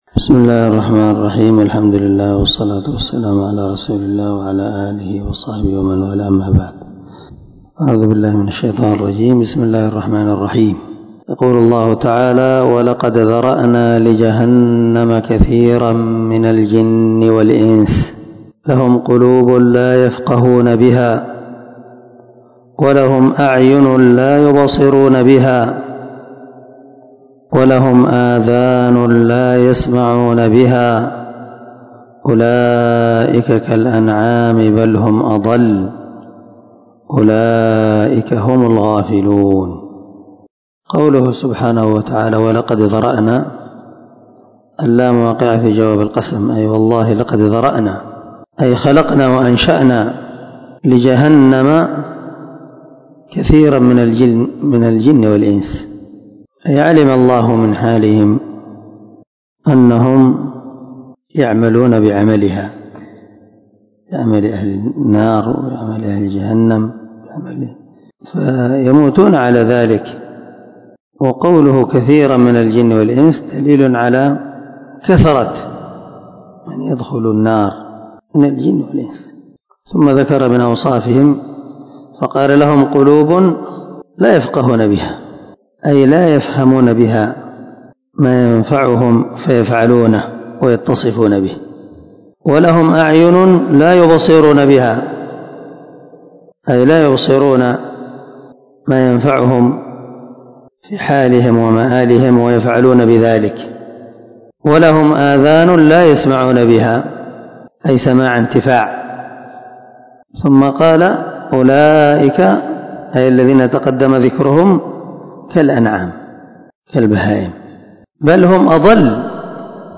494الدرس 46تفسير آية ( 179 ) من سورة الأعراف من تفسير القران الكريم مع قراءة لتفسير السعدي